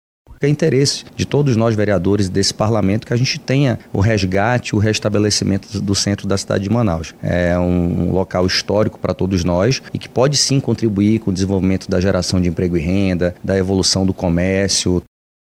O encontro abordou a importância de assegurar o direito ao trabalho desses profissionais, ao mesmo tempo em que se promovem a organização do espaço público, como explica o vereador Rodrigo Sá.